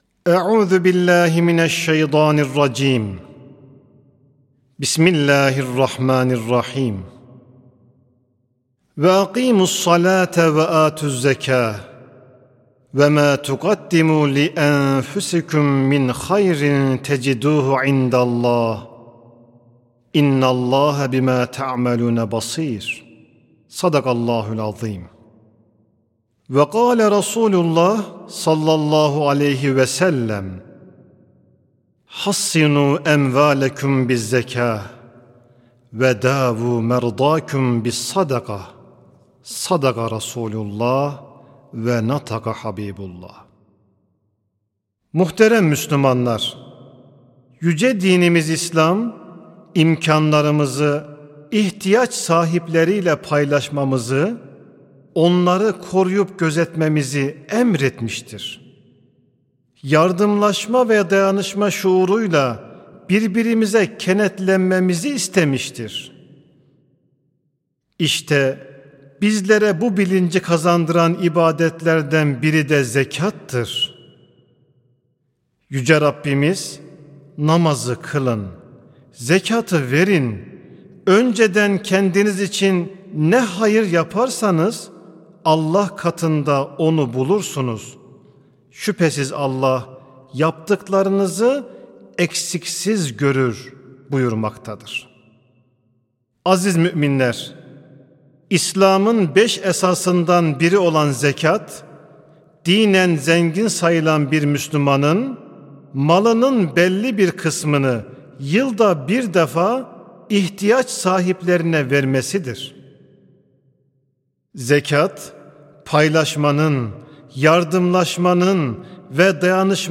Sesli Hutbe (İyilik Köprüsü, Zekat ve Fıtır Sadakası).mp3